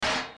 Metal Impact